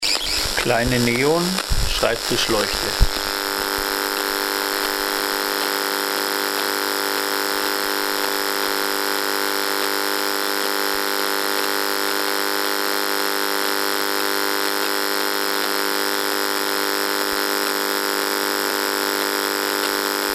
STÖRQUELLEN AUDIODATENBANK
Leuchte Lival Lival 11W Neon Leuchtmittel Low E-Field Netz im Betrieb 100-149